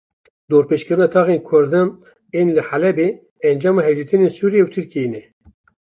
/suːɾɪˈjɛ/